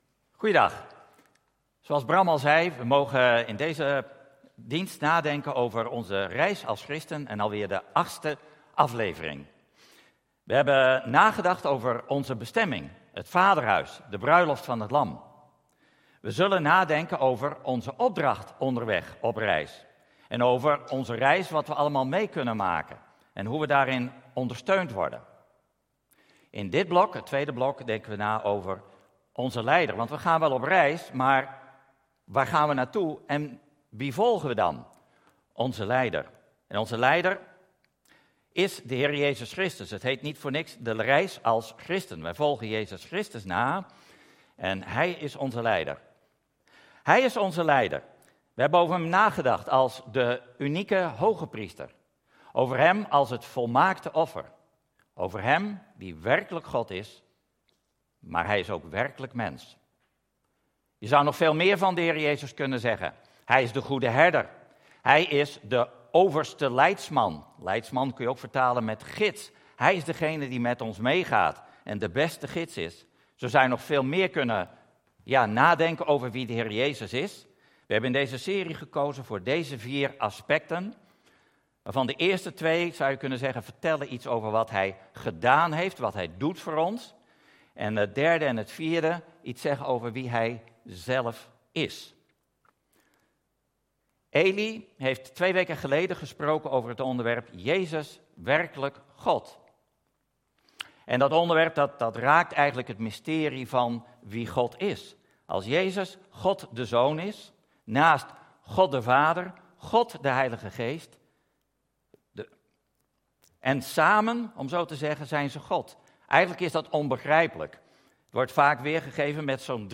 Toespraak 24 januari: Jezus, werkelijk mens - De Bron Eindhoven